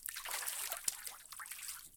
fishline3.ogg